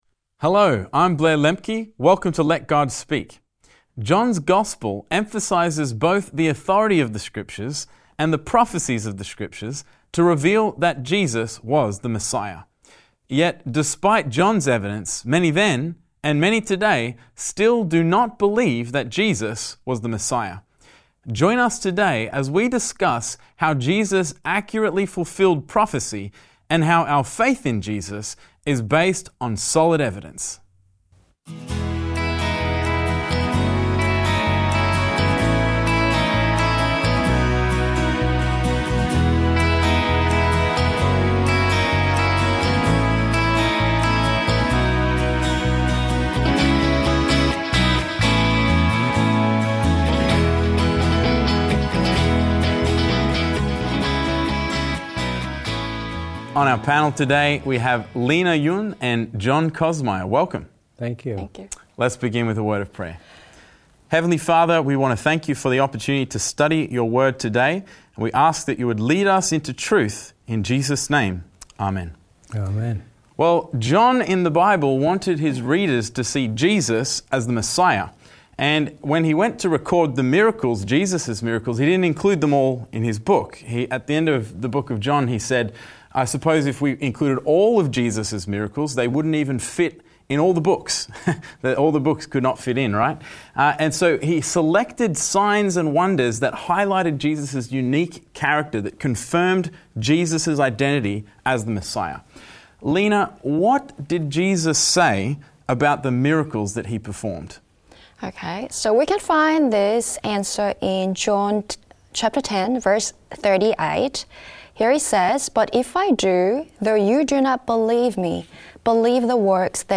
This lesson will discuss how Jesus accurately fulfilled prophecy, and how our faith in Jesus is based on solid evidence.